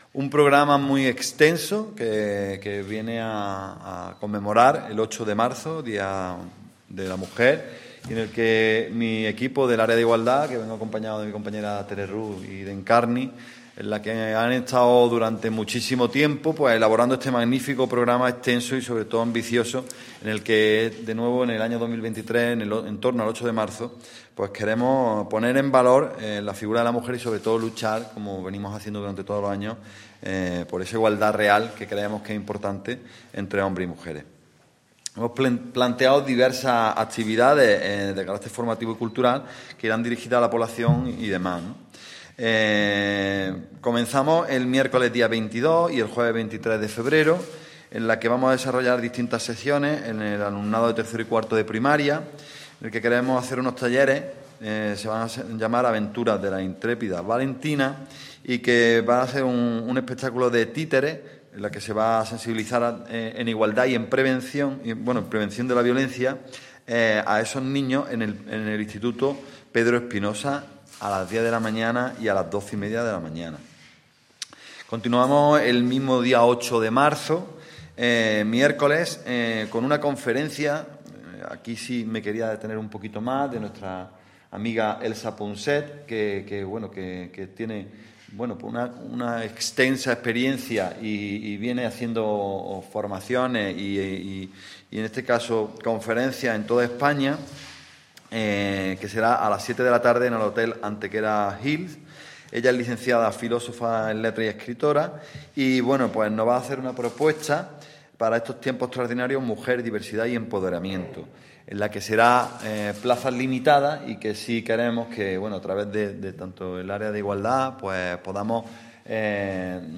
El teniente de alcalde delegado de Programas Sociales e Igualdad, Alberto Arana, ha presentado hoy en rueda de prensa el programa de actividades conmemorativo en nuestra ciudad del Día Internacional de la Mujer.
Cortes de voz